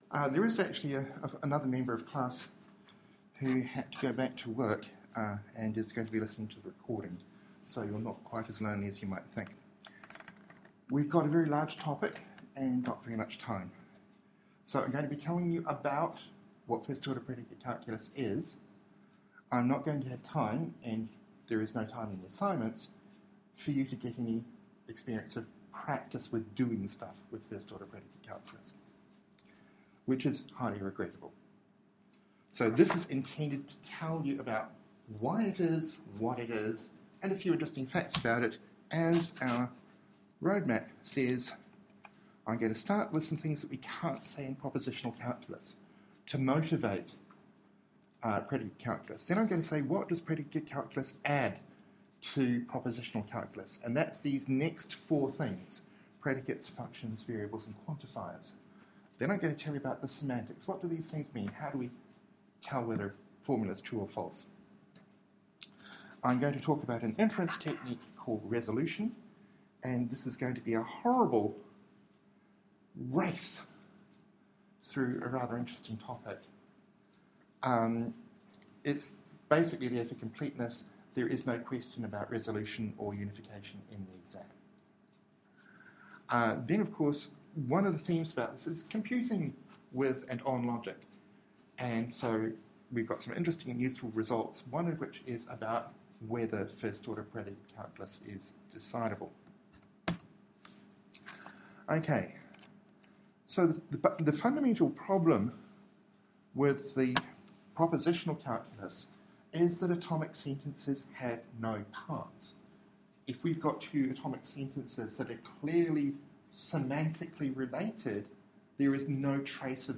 Lecture 11